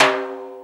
44_25_tom.wav